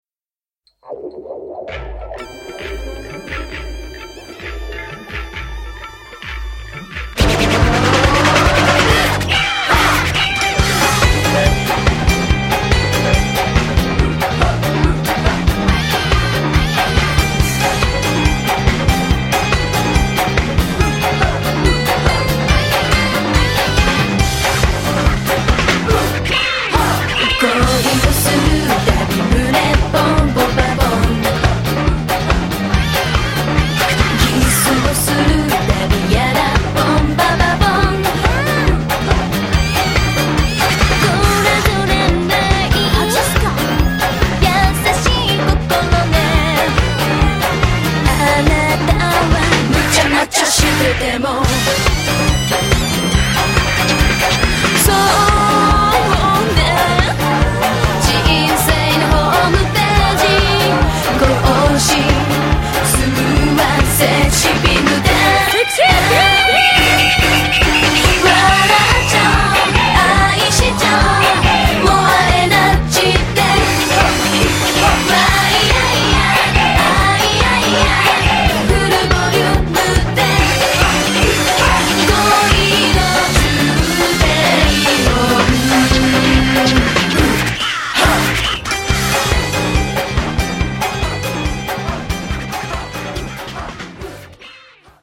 BPM141
Audio QualityPerfect (High Quality)
high-energy